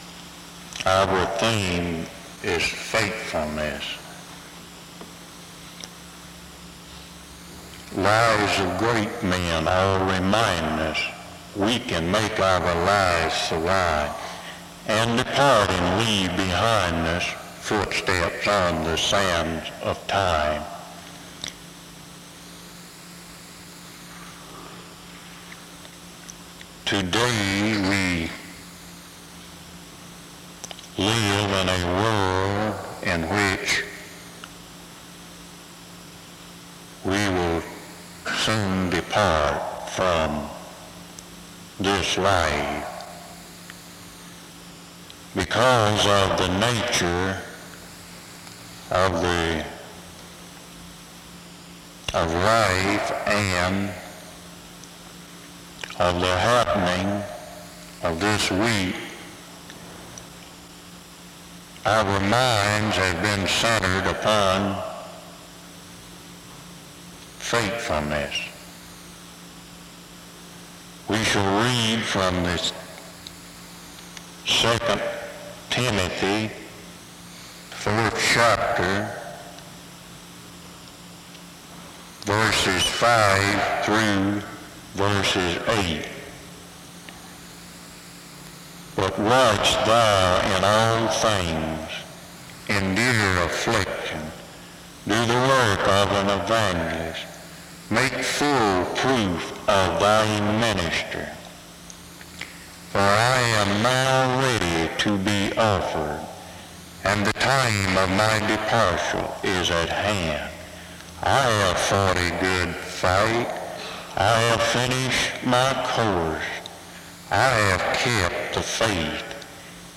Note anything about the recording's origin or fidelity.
The service begins with opening remarks from 0:00-2:20. A prayer is offered from 2:22-4:01. An announcement and a responsive reading takes place from 4:02-6:26.